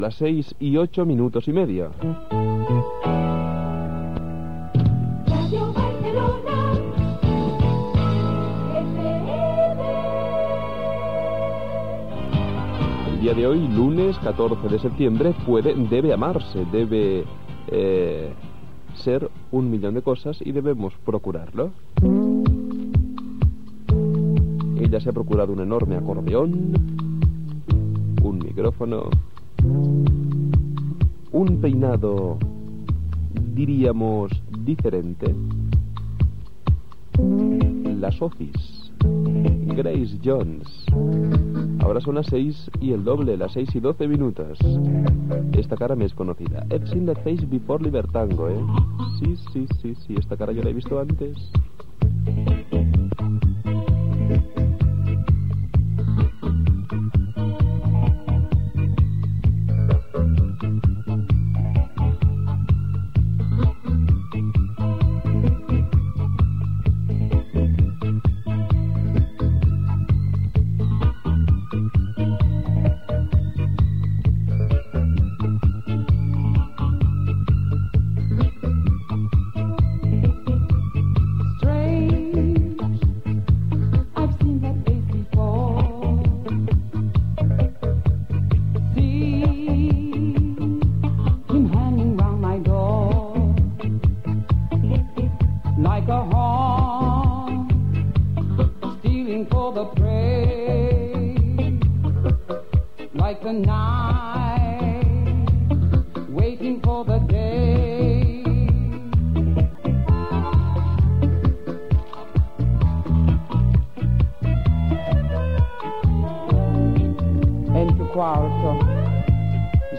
Radiofórmula musical.
Musical